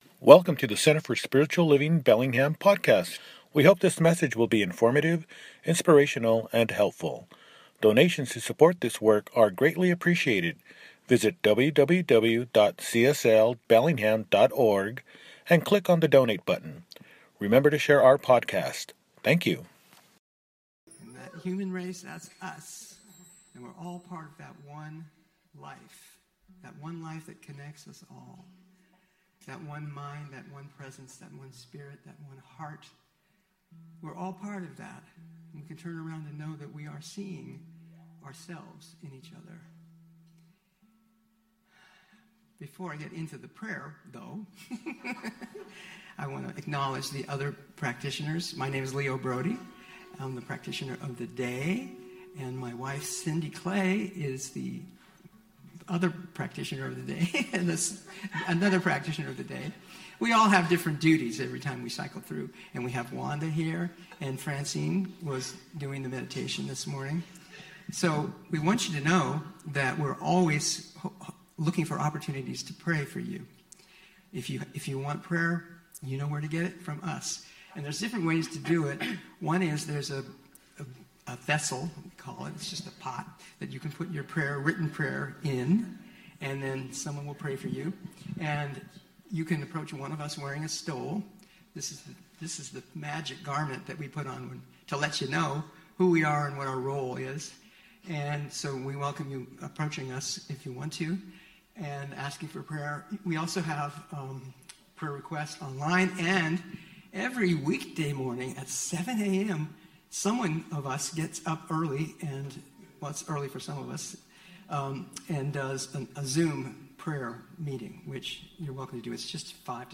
Jul 20, 2025 | Podcasts, Services